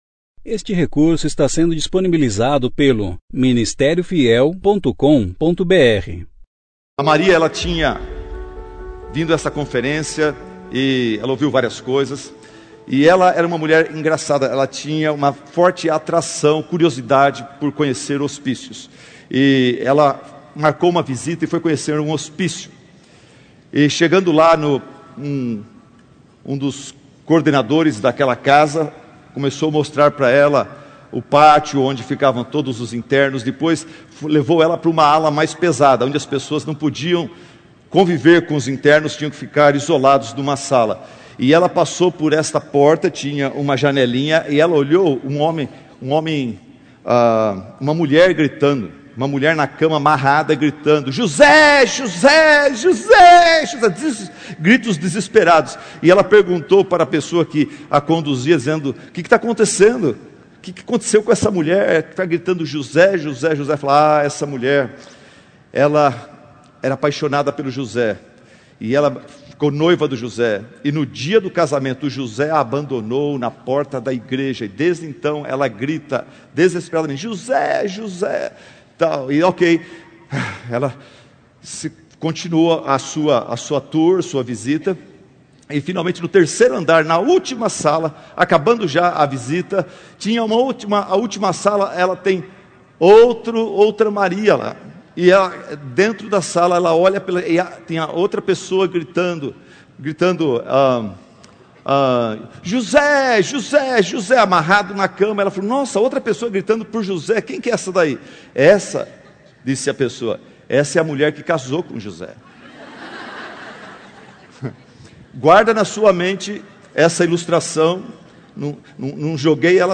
Conferência: 2ª Conferência Fiel para Mulheres – Brasil Tema: Nosso Maior Tesouro Ano: 2017 Mensagem